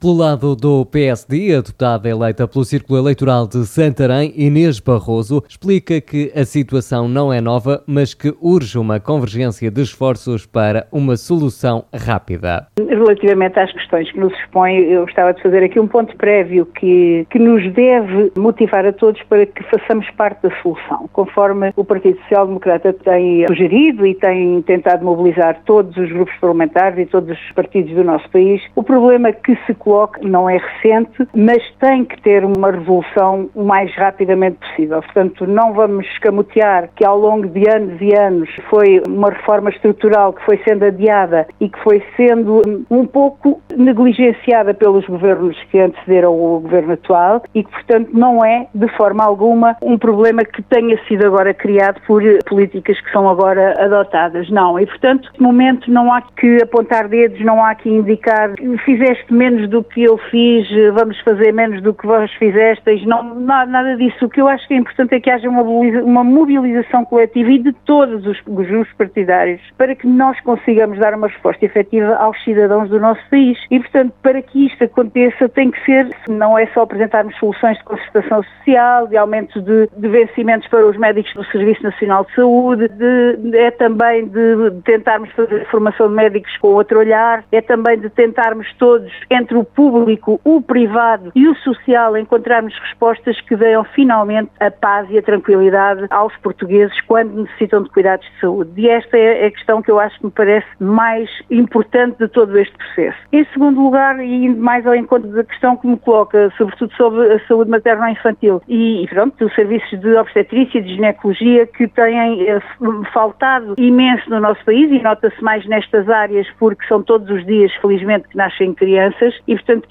Foram convidados deste Especial Informação o Deputado eleito pelo CHEGA, Pedro Frazão, Hugo Costa, do Partido Socialista e Inês Barroso, Deputada eleita pelo Partido Social Democrata.